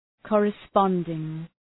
Shkrimi fonetik{,kɔ:rə’spɒndıŋ}
corresponding.mp3